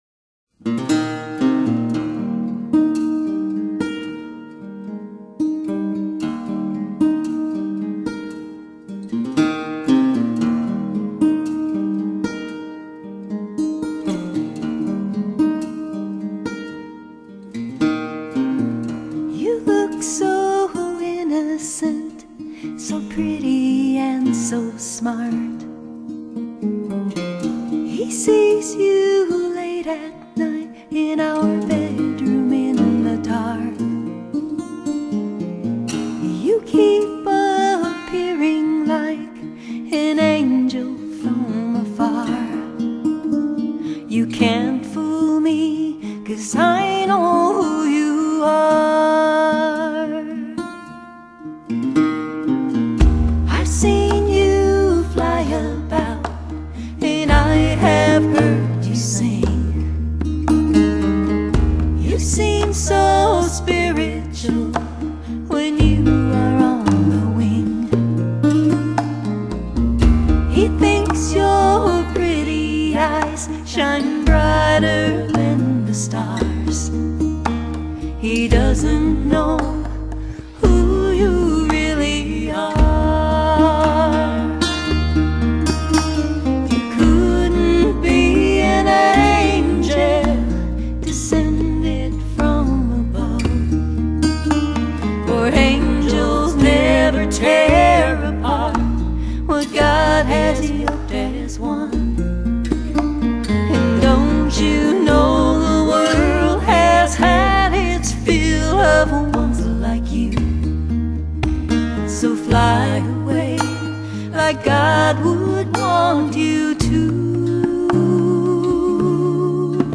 With harmonies galore, they sing and sing.
Recording information: Garage.